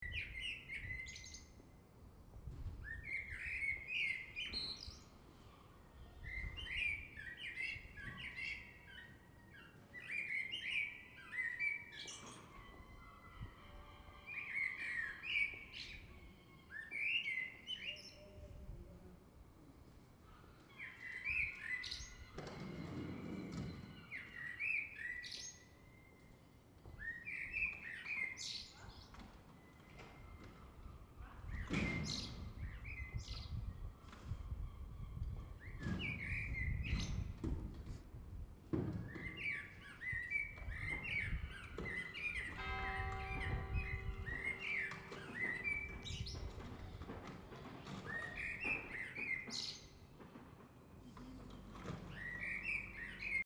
09/03/2015 10:00 Dans le centre ville de Leiden le lundi matin, il fait froid et humide. Les pavés résonnent.